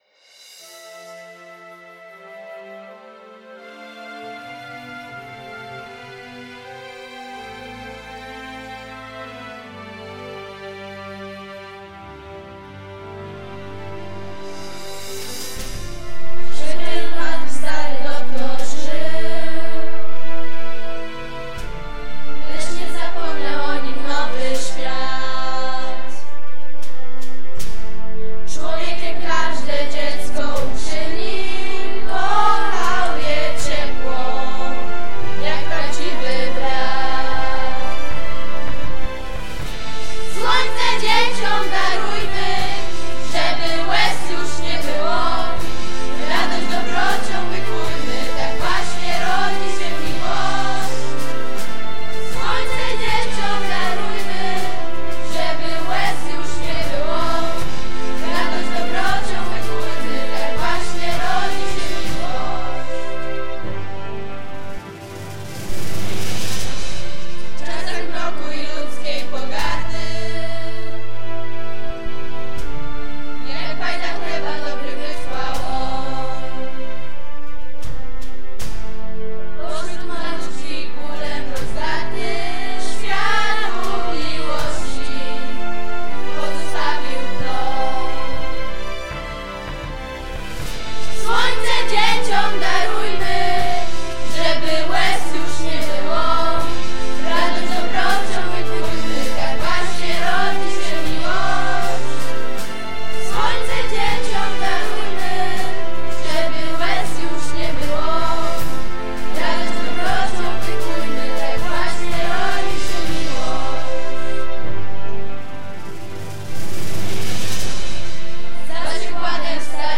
Hymn szkoły ze słowami
hymnszkolyzeslowami.mp3